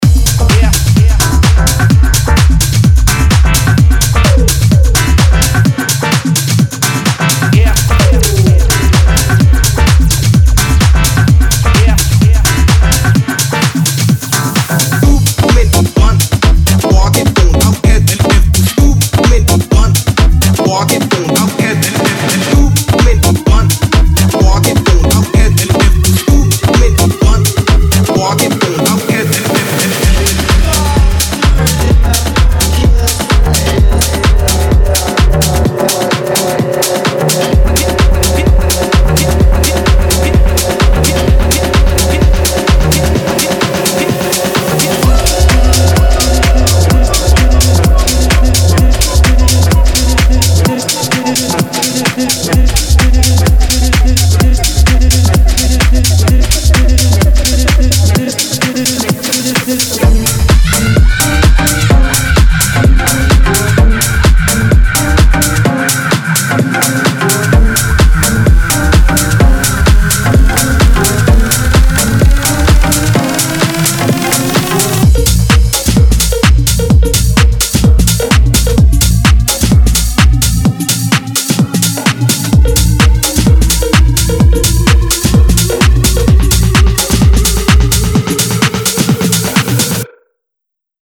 テックハウス系サンプルパックをご紹介いたします。
アナログを駆使したスタジオで制作されたコレクションをお届けします。
パンチの効いたドラムループ、力強いベースライン、そして魅惑的なシンセリフが満載で